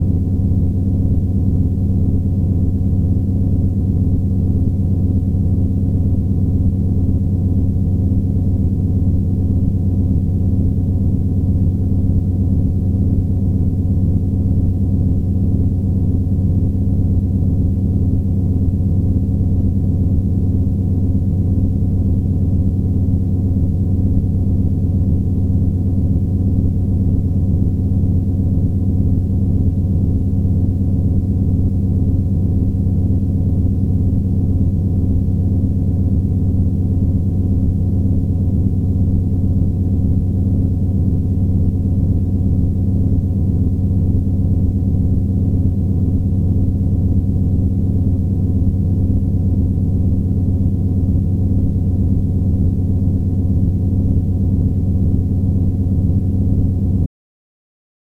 Droning
Threads of noise, tape loops, and samples.
drone-02.wav